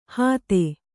♪ hāte